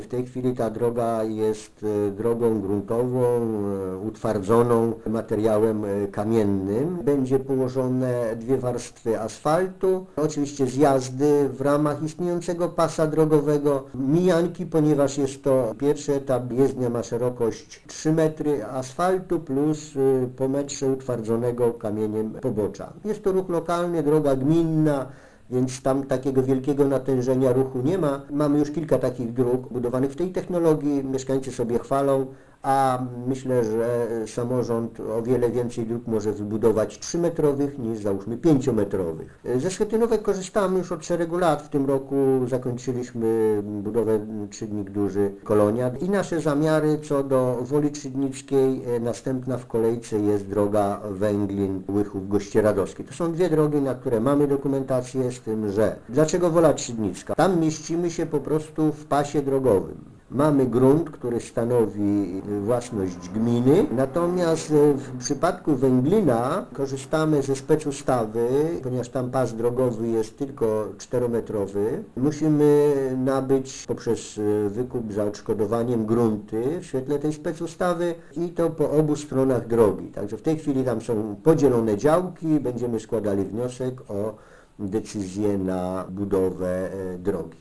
Podczas sesji, która odbyła się w czwartek 12 września radni zgodzili się na złożenie wniosku o dotację na budowę prawie 2-kilometrowego odcinka drogi Wola Trzydnicka - Agatówka - powiedział Informacyjnej Agencji Samorządowej wójt Franciszek Kwiecień: